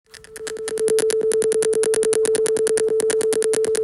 Playing Sine Wave
When I ran this code, I heard loud clicking sounds instead of a clean tone.
Pulsing sound artifact